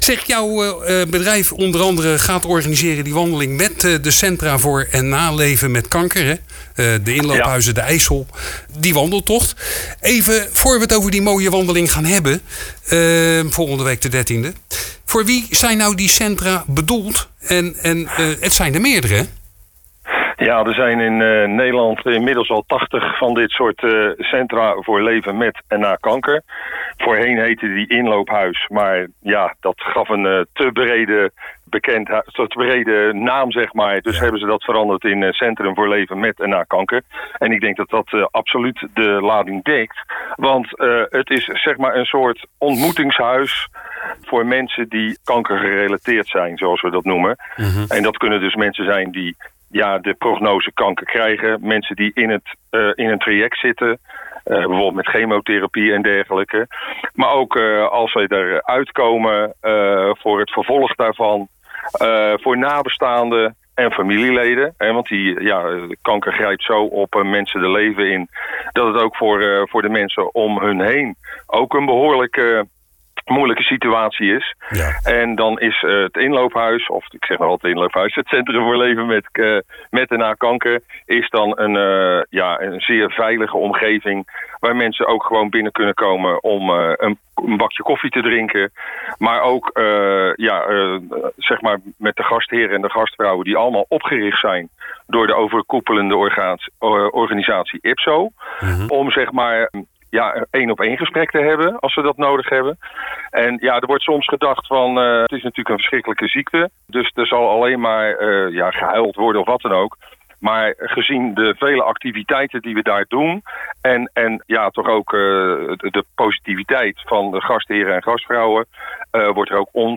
Vrijdagavond 13 december a.s. wordt voor de Centra voor leven met en na kanker weerde Lichtjes Wandeling Schollebos georganiseerd.�In deze podcast een gesprek